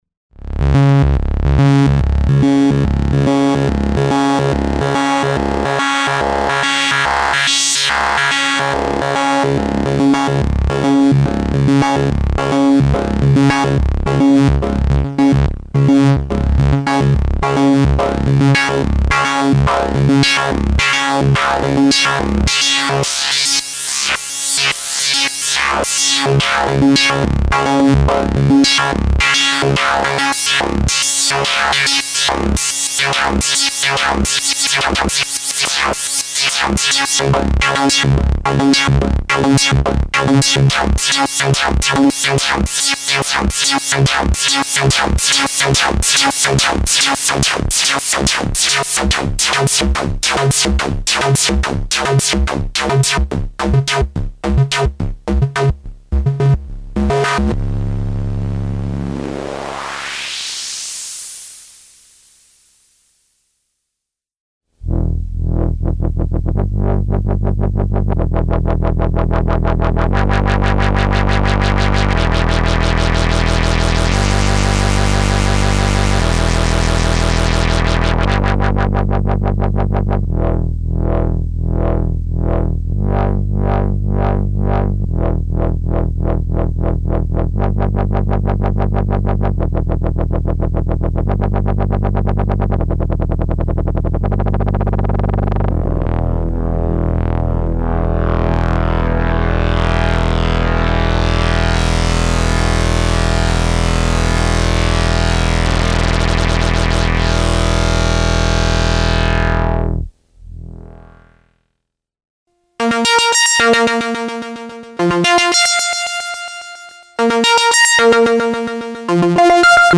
editSOUND a monophonic synthesizer based on subtractive synthesis.